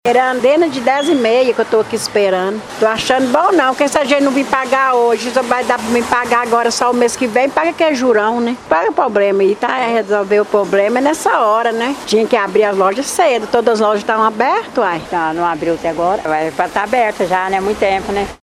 O JM conversou com duas senhoras que vieram da zona rural somente para fazer o pagamento numa destas lojas e acabaram perdendo parte do dia, na porta, esperando até a abertura do estabelecimento.